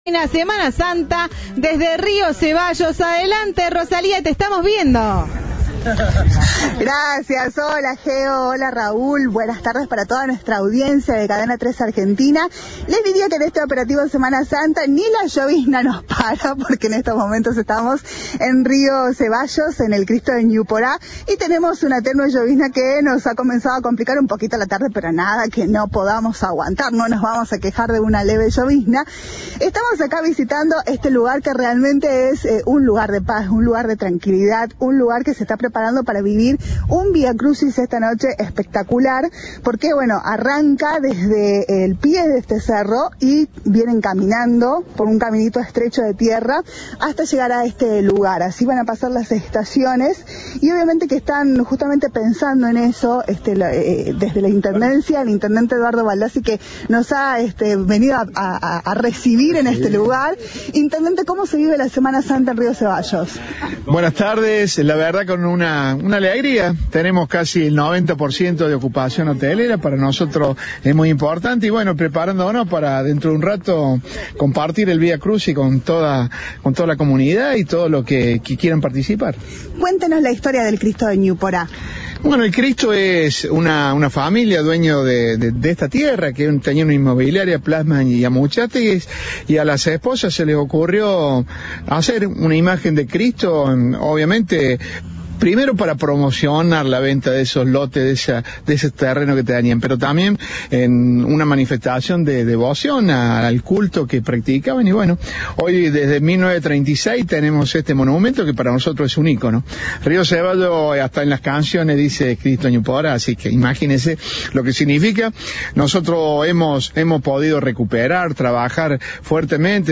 El intendente Eduardo Baldassi dijo a Cadena 3 que el ascenso se realiza por un camino estrecho y que esperan a toda la comunidad para que participe del evento.
Informe